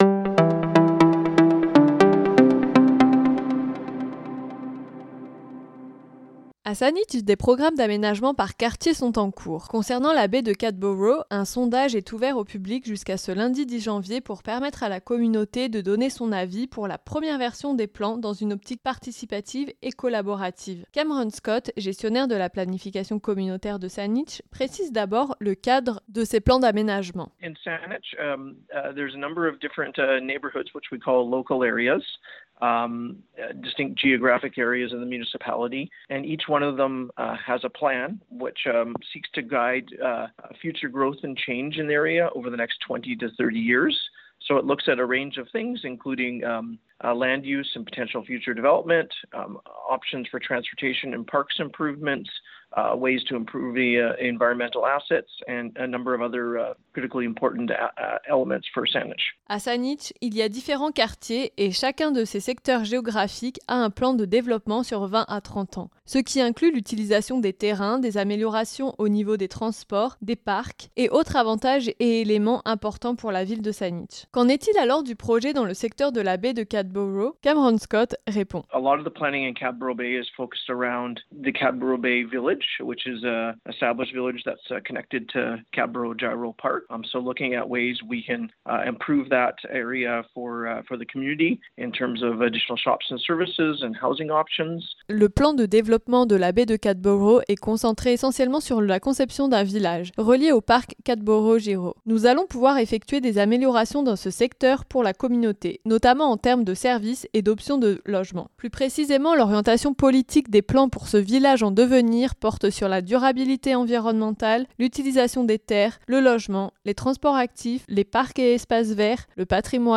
(Photo : Municipalité de Saanich) Il s’agit d’un projet qui vise plus particulièrement à développer le village de la baie de Cadboro. Pour en savoir plus, voici le reportage…